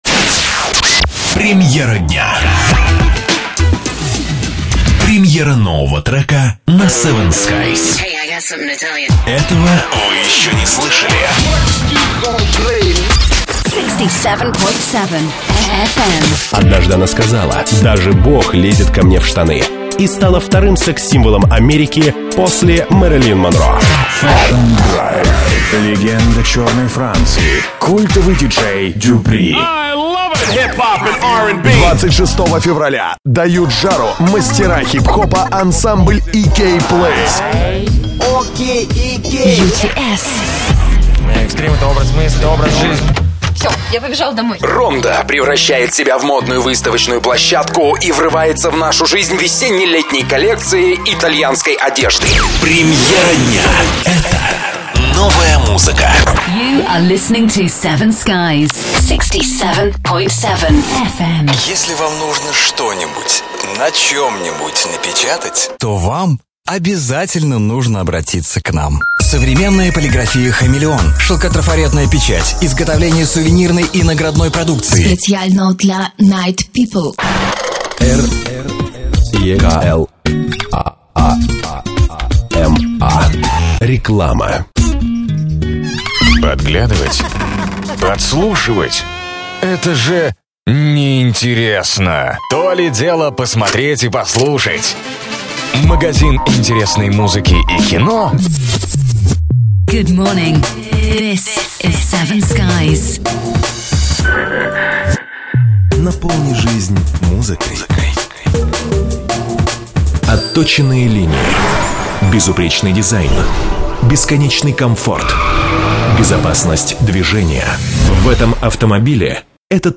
Аудио реклама. Оформление эфира.